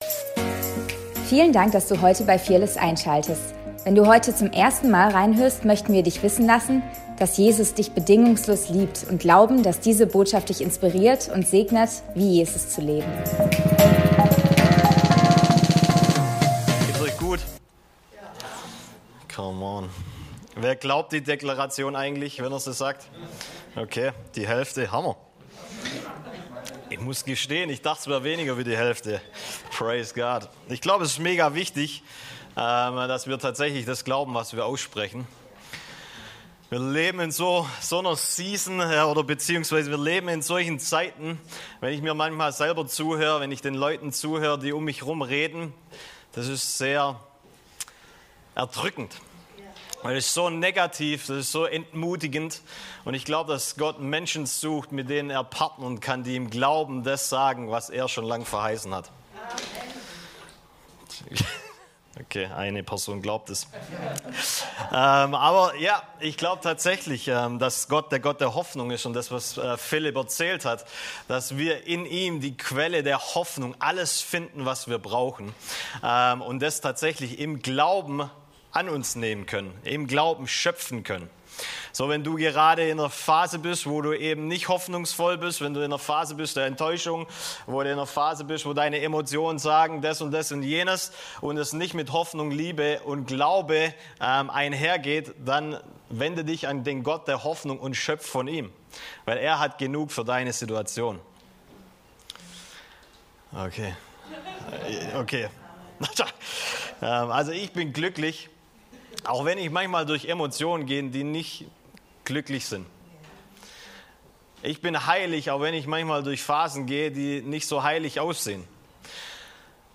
Predigt vom 01.06.2025